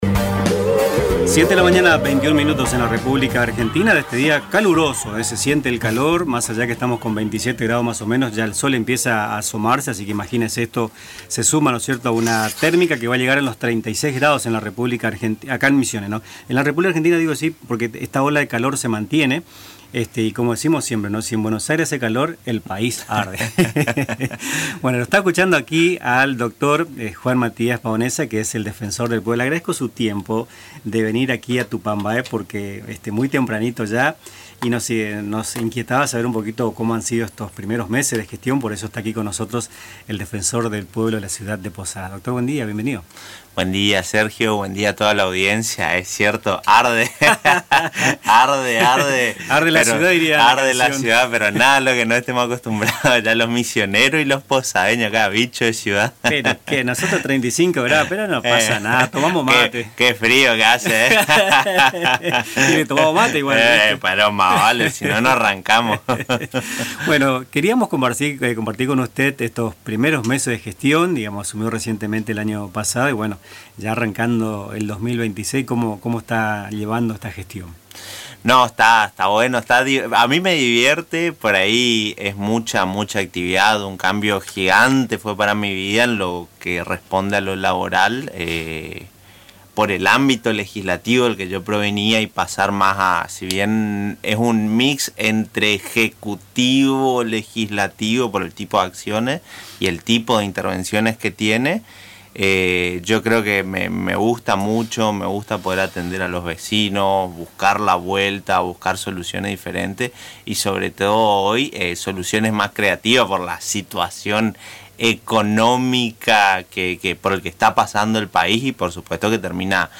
Escuchá la entrevista con el Defensor del Pueblo de Posadas, Juan Matías Paonessa, en Tupambaé: https